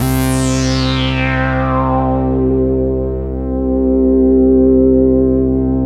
SYN JD-8002L.wav